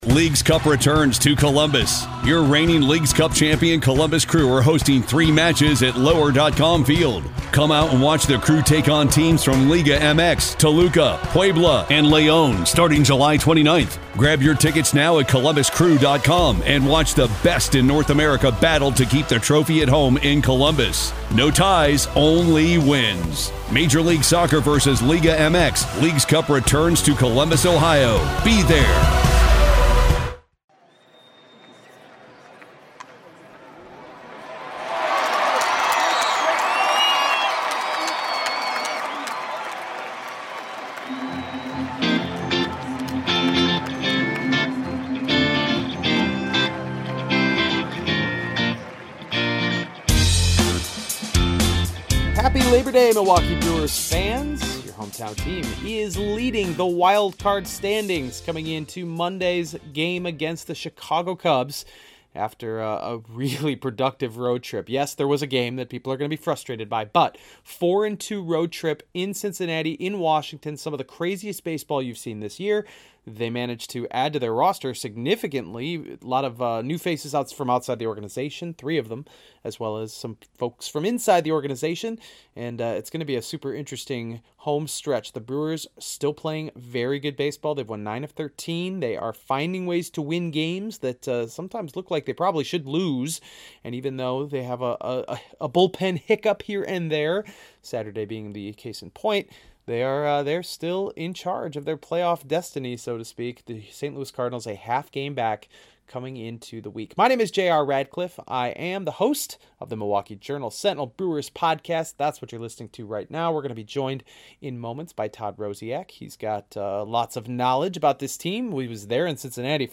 Sound effect: WALLA Ballpark Cheer William Tell Overture Organ